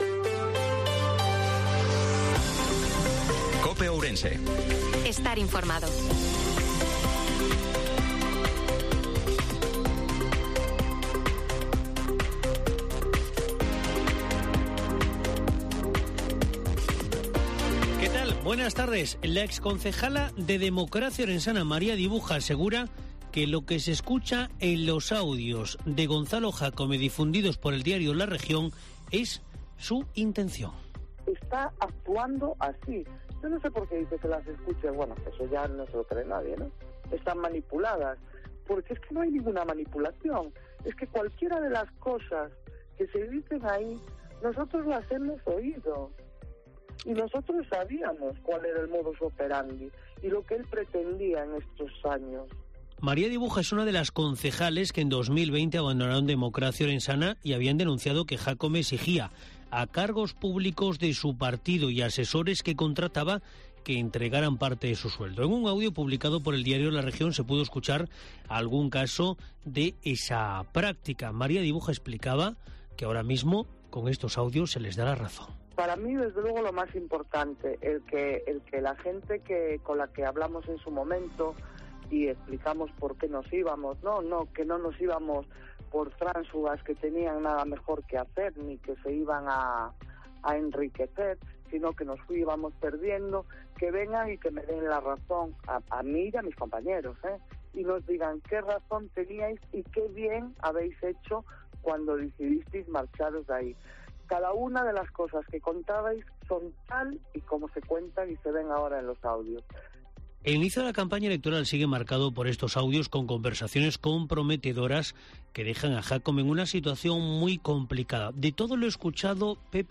INFORMATIVO MEDIODIA COPE OURENSE-15/05/2023